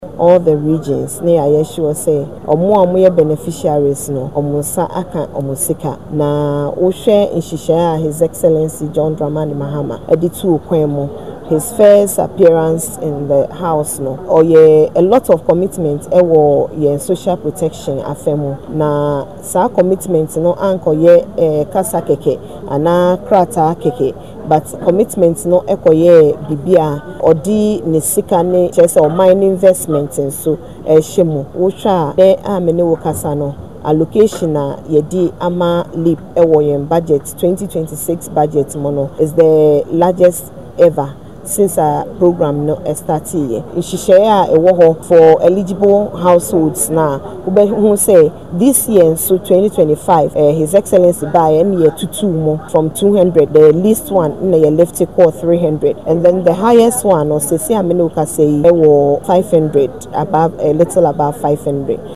During a recent press briefing, the Ministry highlighted its ongoing efforts to disburse funds to deprived individuals through targeted initiatives.